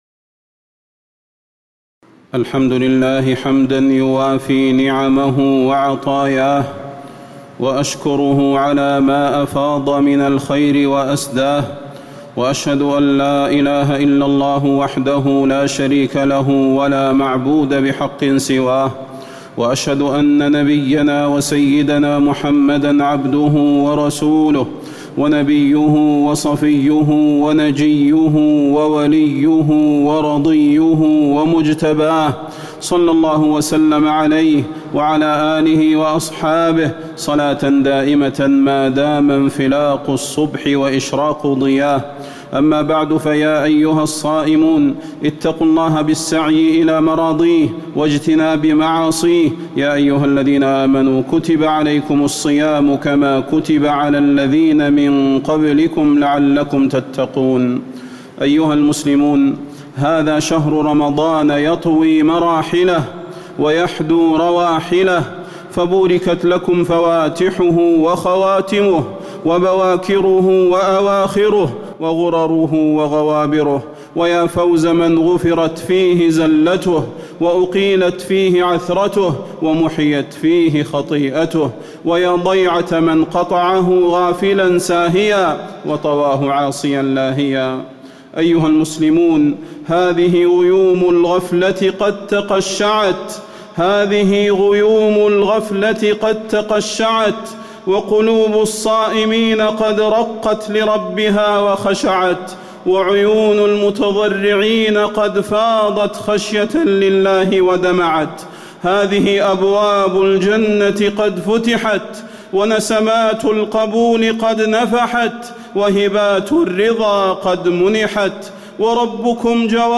تاريخ النشر ١٩ رمضان ١٤٣٧ هـ المكان: المسجد النبوي الشيخ: فضيلة الشيخ د. صلاح بن محمد البدير فضيلة الشيخ د. صلاح بن محمد البدير العشر الأواخر من رمضان